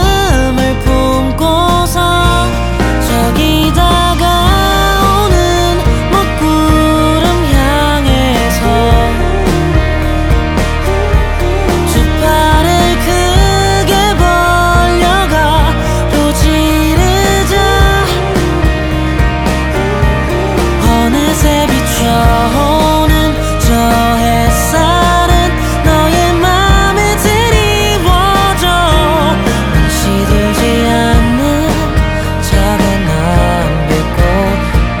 Жанр: Музыка из фильмов / Саундтреки
# TV Soundtrack